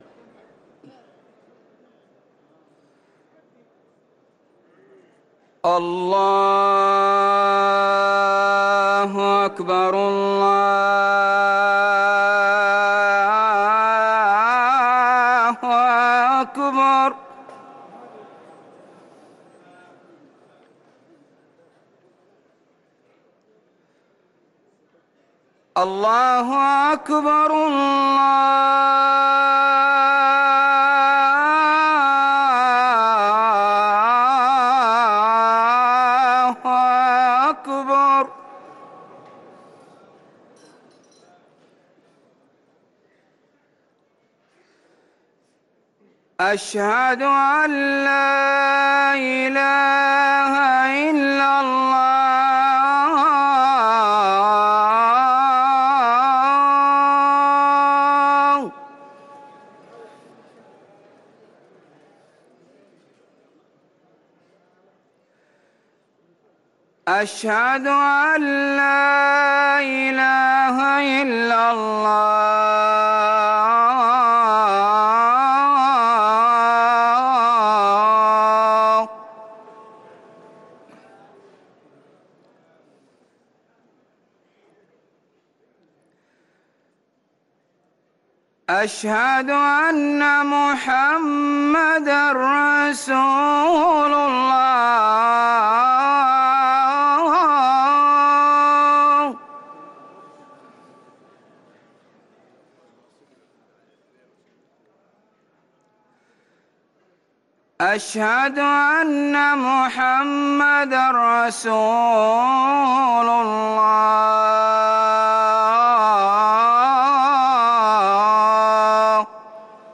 أذان العشاء للمؤذن سعود بخاري الخميس 1 رمضان 1444هـ > ١٤٤٤ 🕌 > ركن الأذان 🕌 > المزيد - تلاوات الحرمين